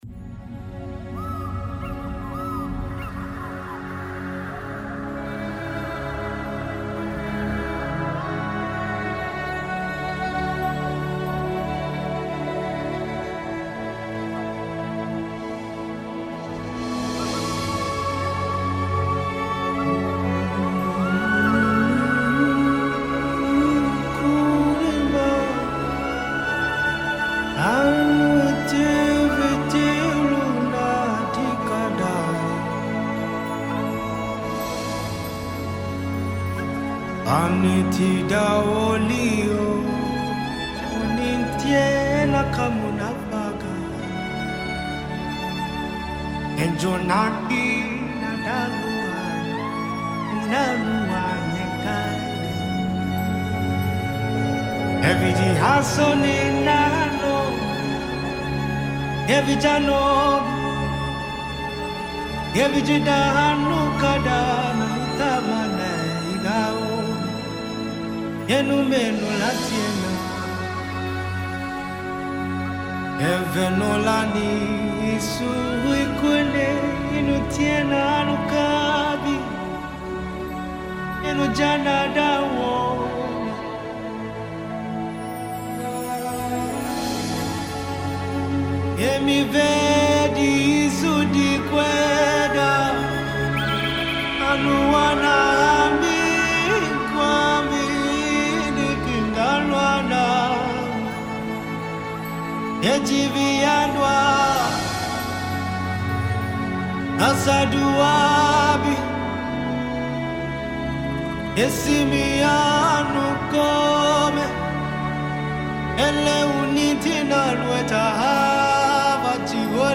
spiritual chant